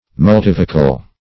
Search Result for " multivocal" : The Collaborative International Dictionary of English v.0.48: Multivocal \Mul*tiv"o*cal\, a. [Multi- + vocal.]